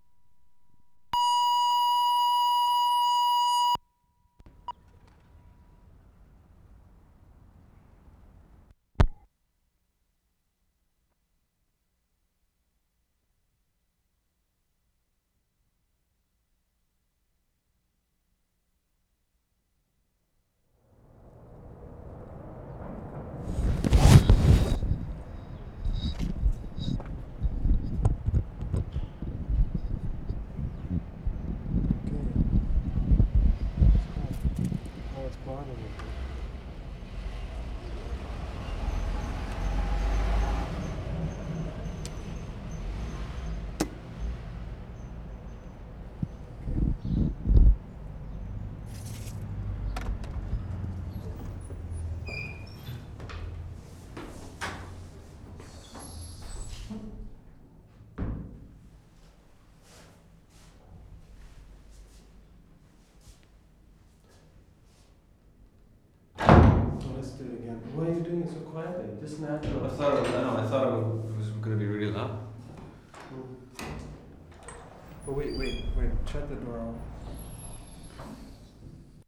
QUEBEC CITY, QUEBEC Oct. 28, 1973
AMBIENCE IN FOYER OF CONVENT (URSULINES) 0'59"
3. Recordists entering building, note the incredible quietness inside.